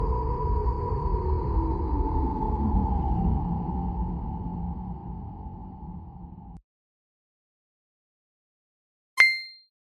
Free Ambient sound effect: Desert Wind.
006_desert_wind.mp3